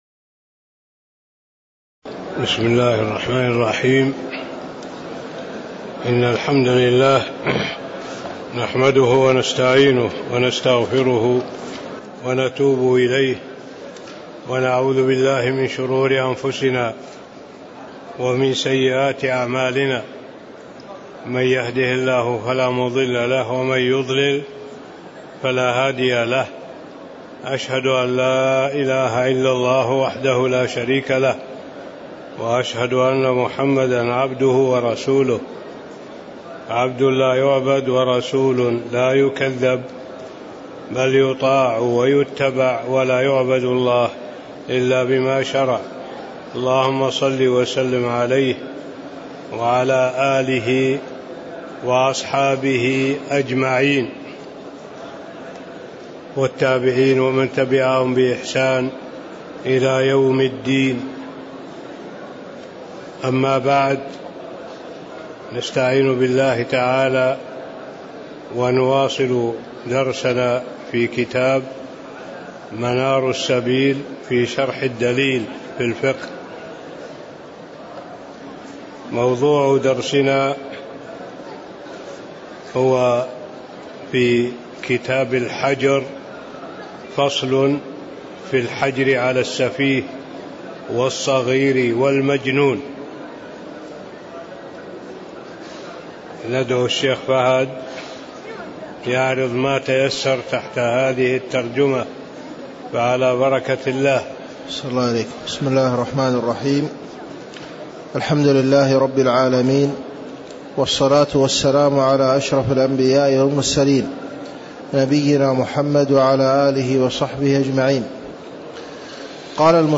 تاريخ النشر ٣ صفر ١٤٣٧ هـ المكان: المسجد النبوي الشيخ